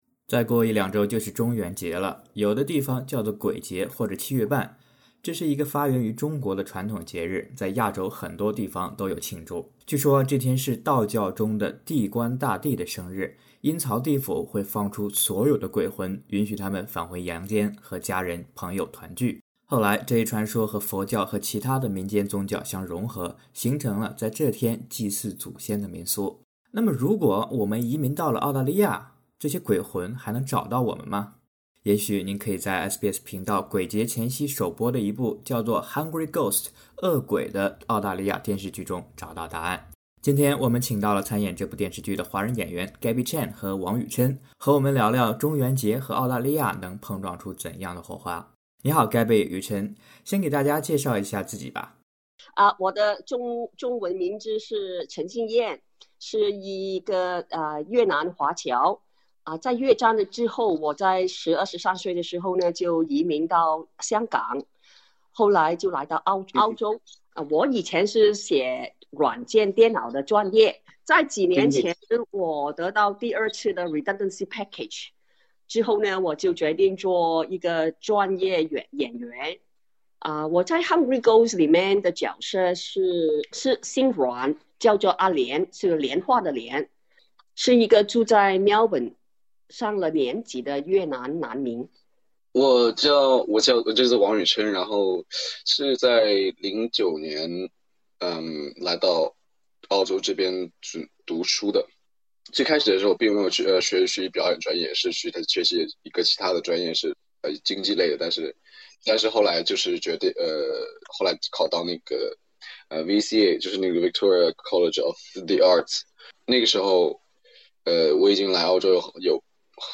《Hungry Ghosts》演員專訪：澳洲熒幕上的亞洲恐怖故事